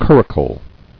[cur·ri·cle]